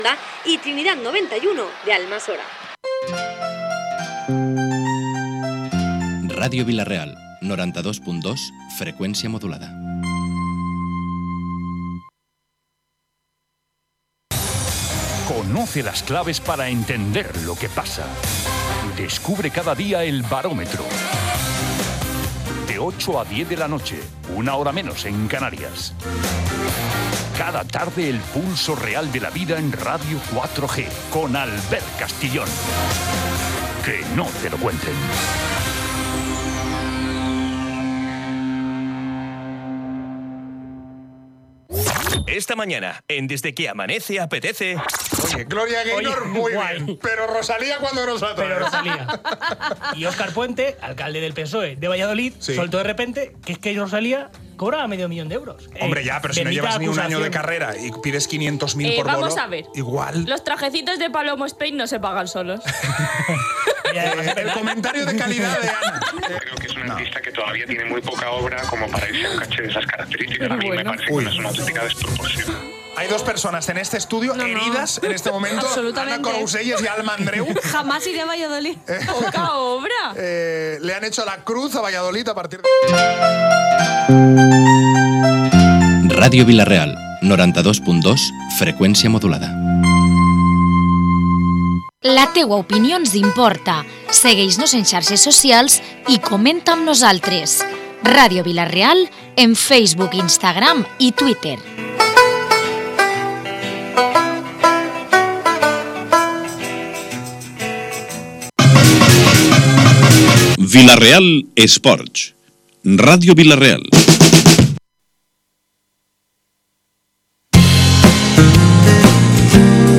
La tertúlia de los lunes en Ràdio Vila-real.